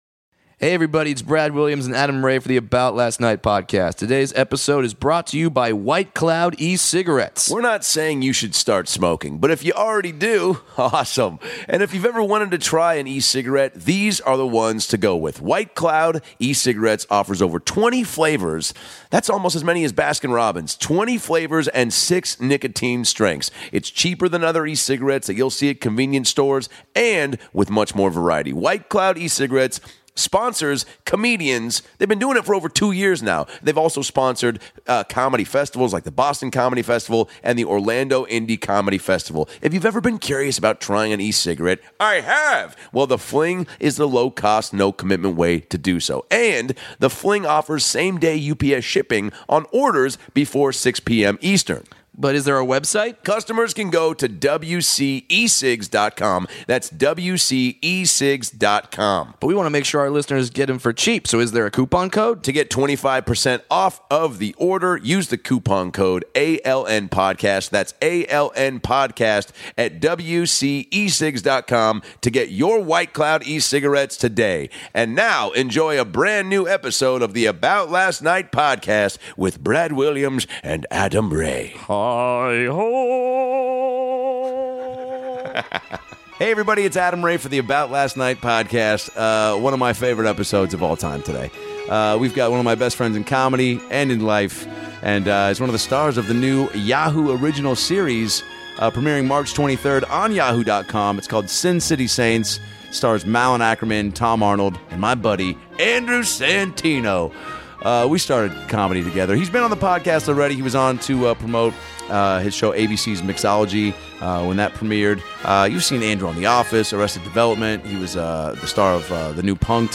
He returns to the podcast to talk about his first late night appearance on CONAN, how he almost died in Vegas shooting his new TV show, and delivers the first good Adam Ray impression ever. There's a lotta laughs in this one.